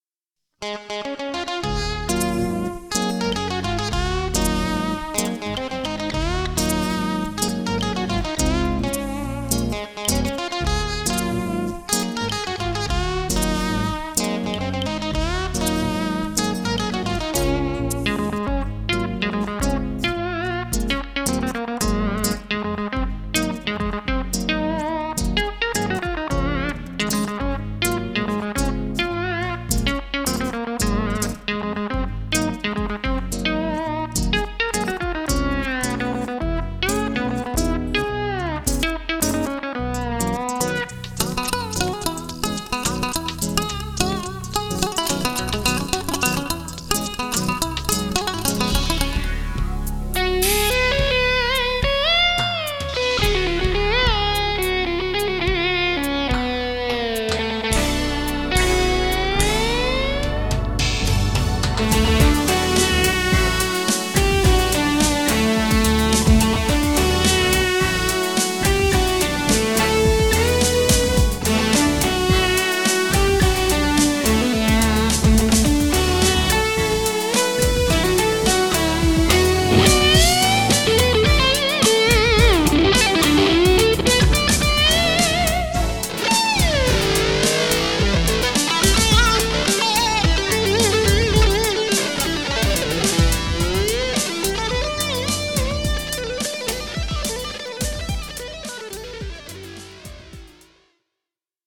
Remixed version of an earlier upload just showing some more fretless sounds.
All melodies and lead parts played on a fretless guitar, using DIY electronics
mixed with yesterday's commercial stompboxes.
A convertible baritone guitar with separate outputs for the bass and top strings
was used to play chords and basslines simultaneously from 00:00 - 00:50.